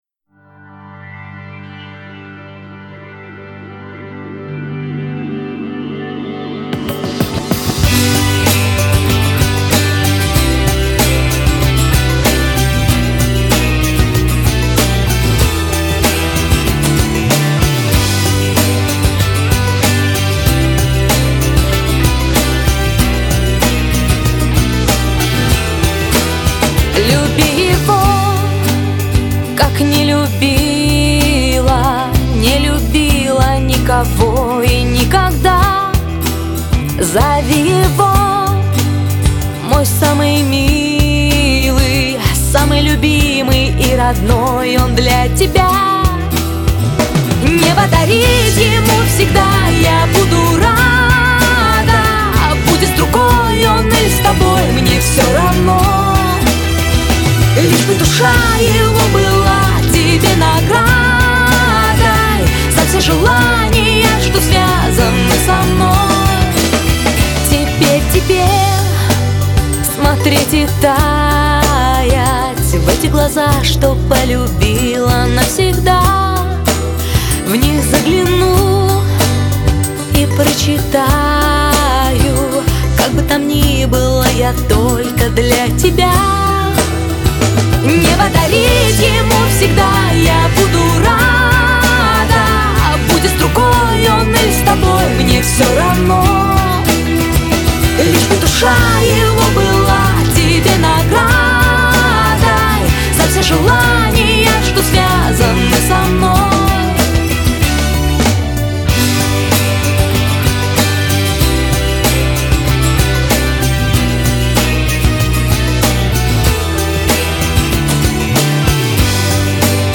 (ремикс)